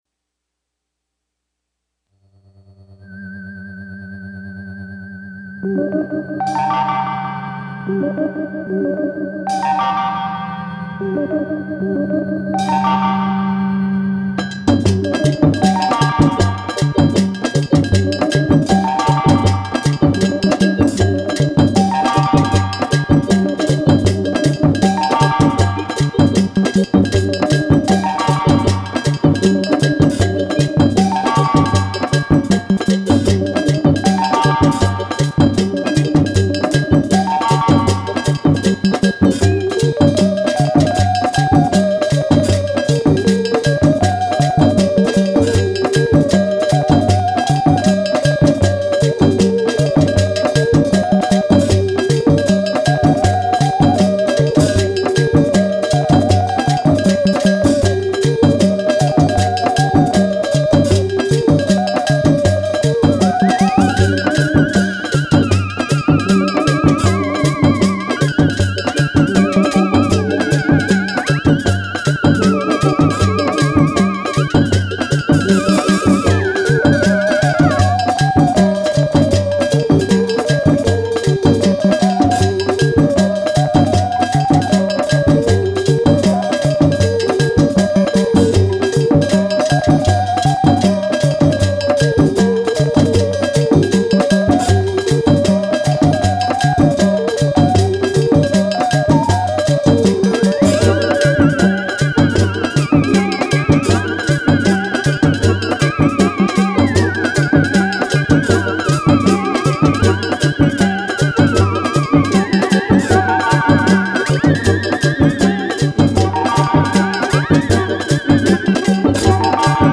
mélange fou de sons psychédéliques